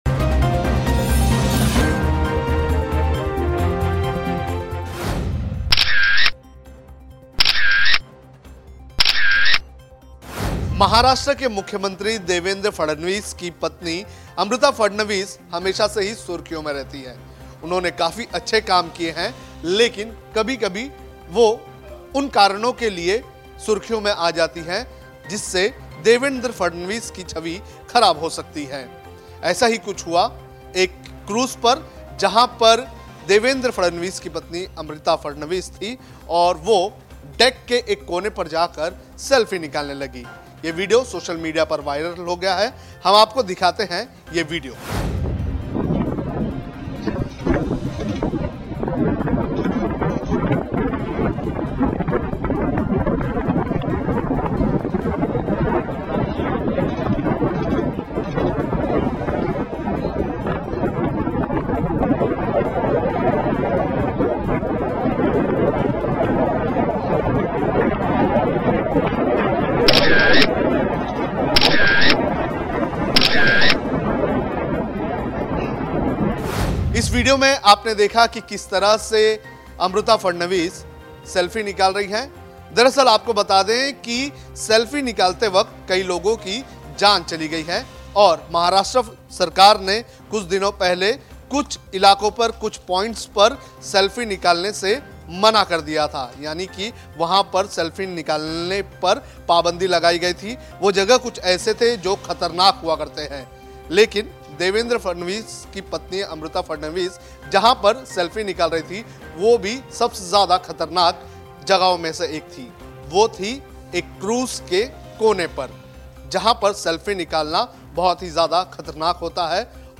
न्यूज़ रिपोर्ट - News Report Hindi / अमृता फडणवीस की सेल्फी महाराष्ट्र के मुख्यमंत्री देवेंद्र फडणवीस को पड़ सकती है महंगी